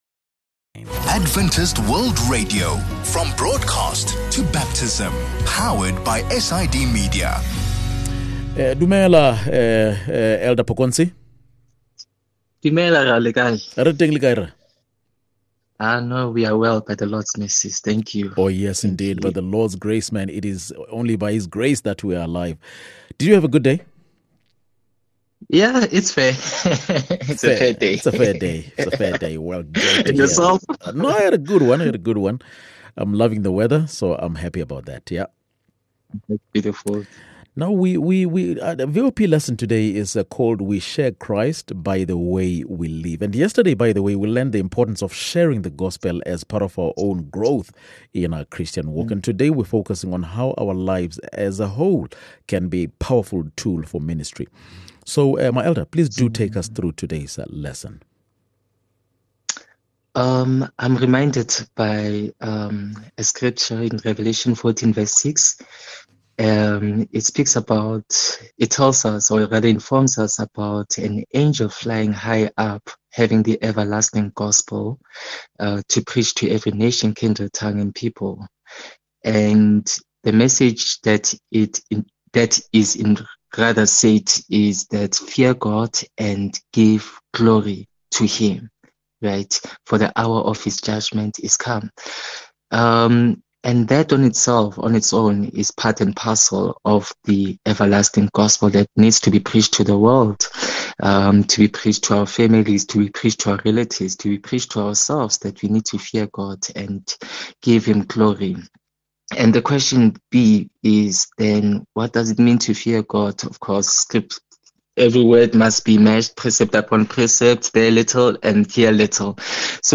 20 Aug VOP Lesson | We Share Christ by the Way We Live